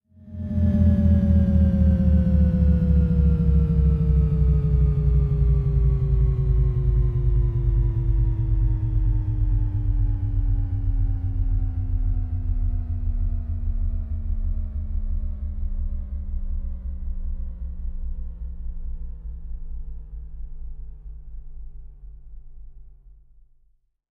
poweroff.ogg